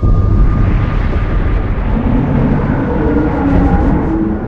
Alarm2_8.ogg